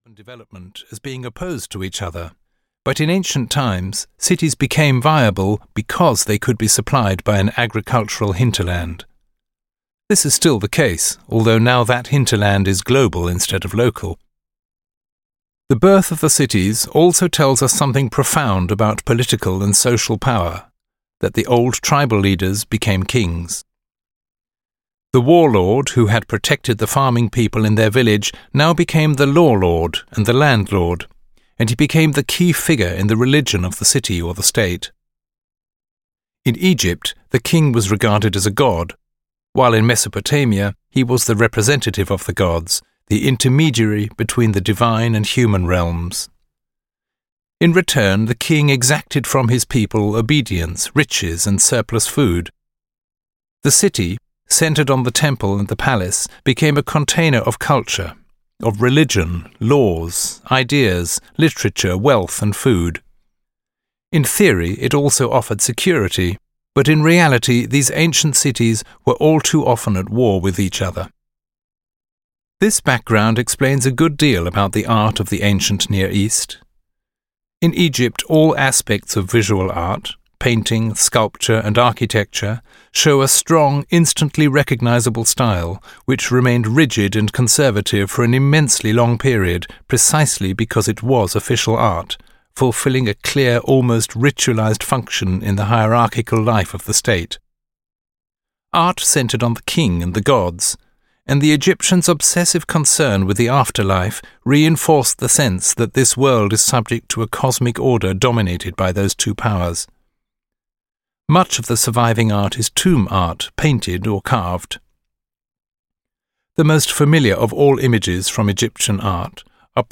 The History of Western Art (EN) audiokniha
Ukázka z knihy